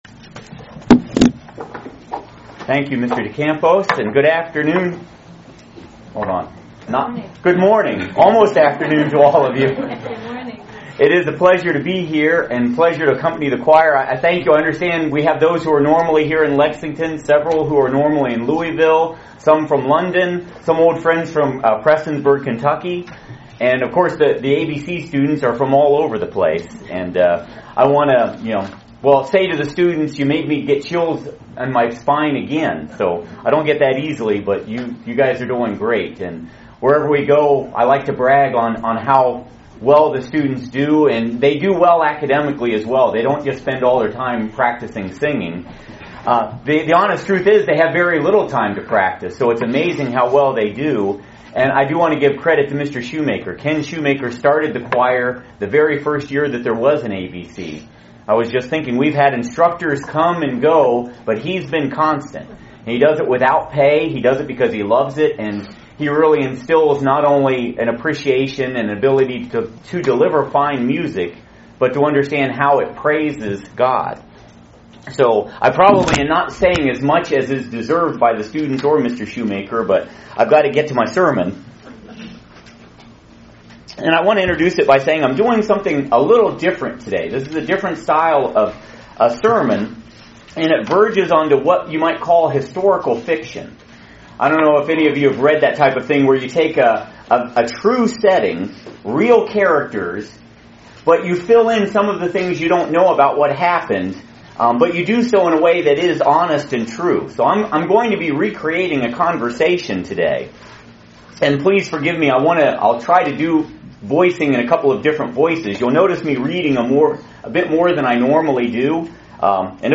A different kind of Sermon using Historical Fiction in a very Interesting conversation between 3 fictional 1st century Christians. A very Inspiring look at the Sacrifice of Jesus Christ from a First Century perspective.